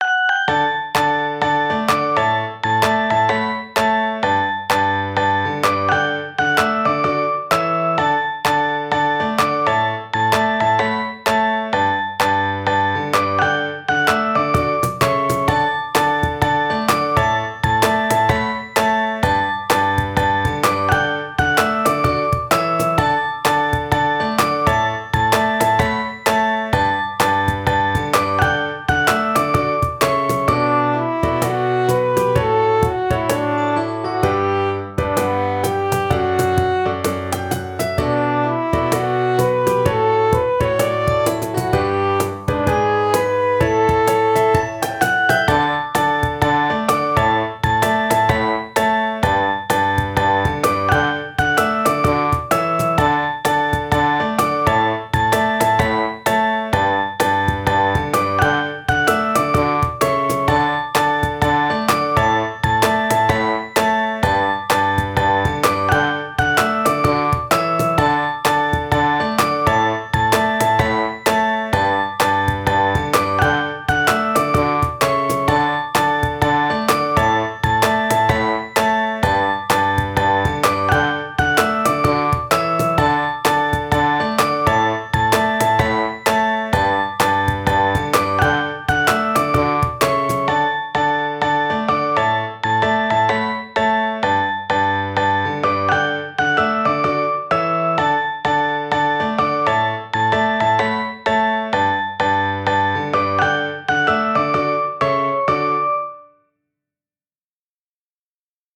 可愛くて、ほのぼのとした日常BGMです！